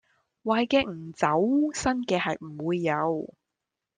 Голоса - Гонконгский 470
Гонконгский 470